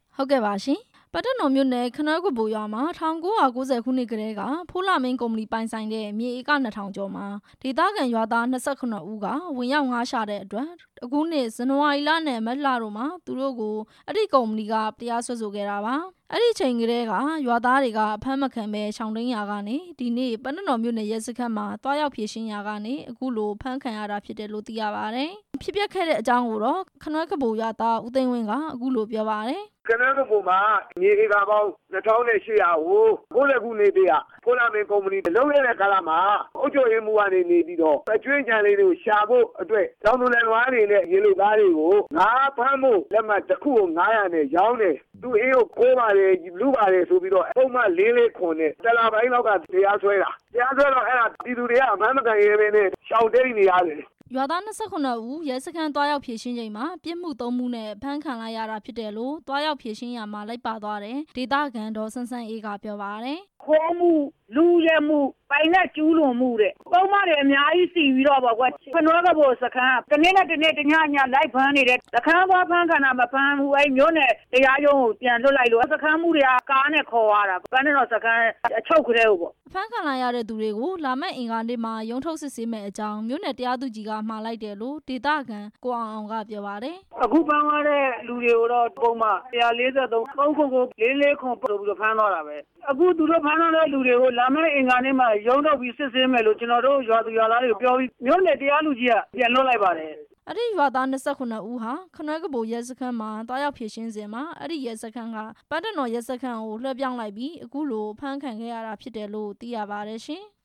ခနွဲကဗိုရွာသားများ အဖမ်းခံရတာနဲ့ ပတ်သက်ပြီး တင်ပြချက်